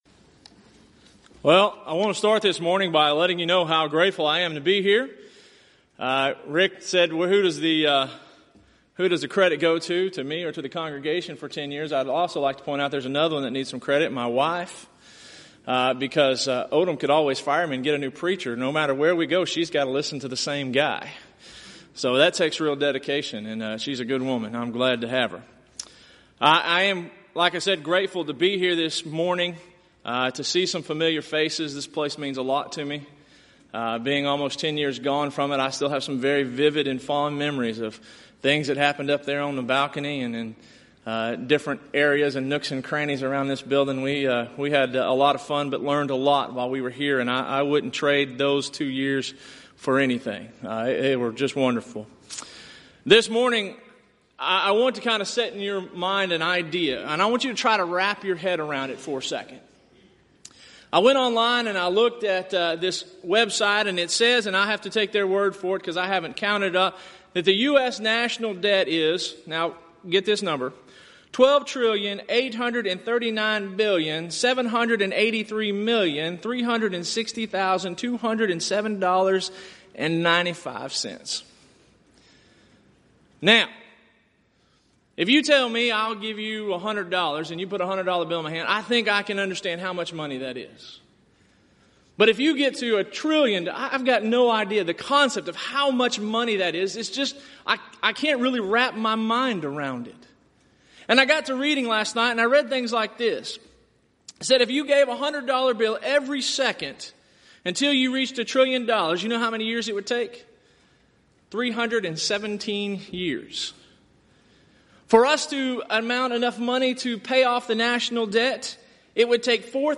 Event: 29th Annual Southwest Lectures